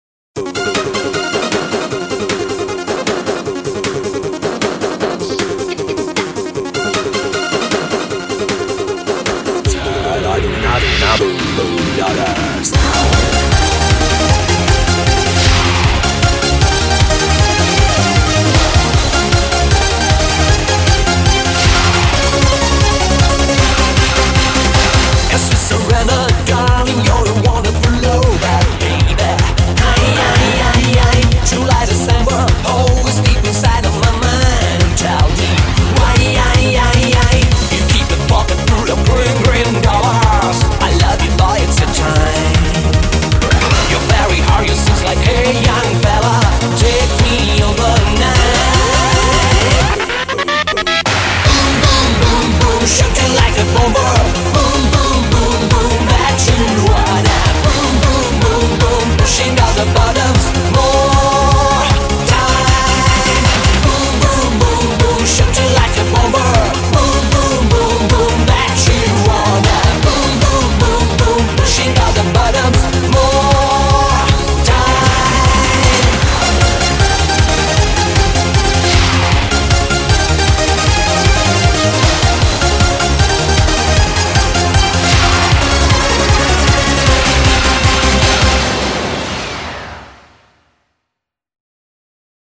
Audio QualityPerfect (High Quality)
remix